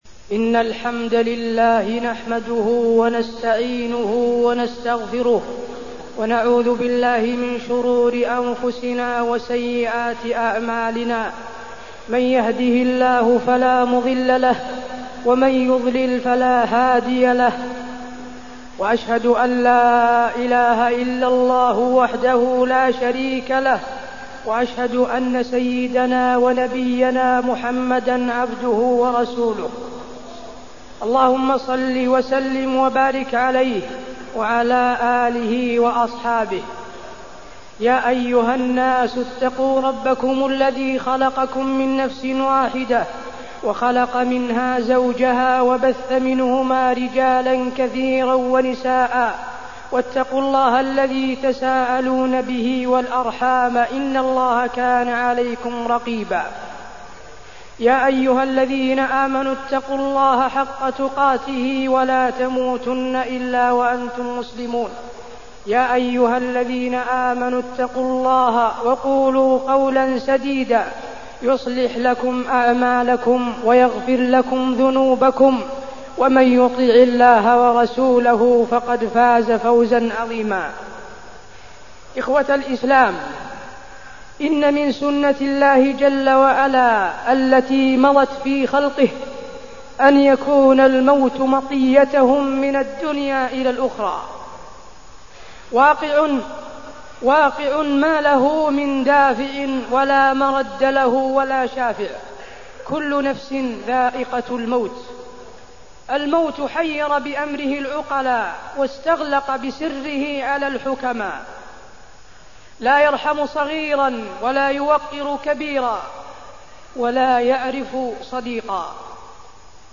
تاريخ النشر ٢ رجب ١٤٢١ هـ المكان: المسجد النبوي الشيخ: فضيلة الشيخ د. حسين بن عبدالعزيز آل الشيخ فضيلة الشيخ د. حسين بن عبدالعزيز آل الشيخ الموت وتخصيص شهر رجب بعبادة